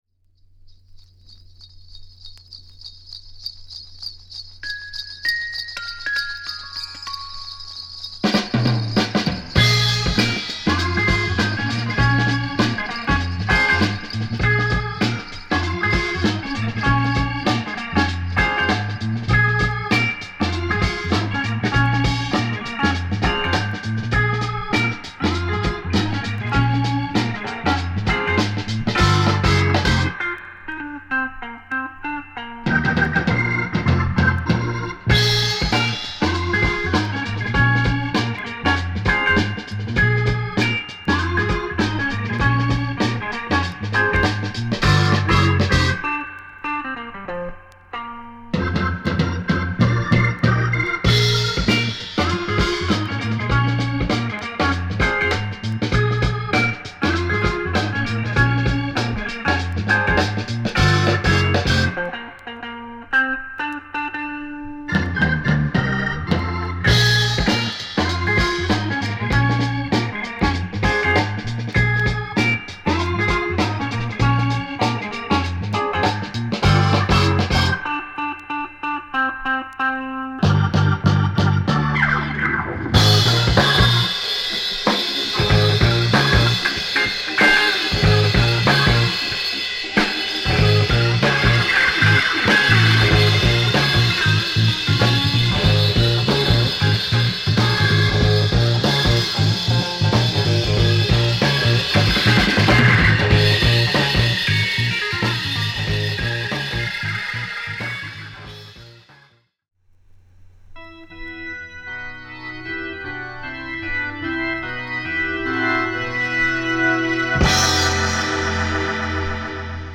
鈴の音や、クリスマスっぽいフレーズを交えながらもファンクネスは保った流石の2曲を収録！